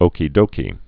(ōkē-dōkē)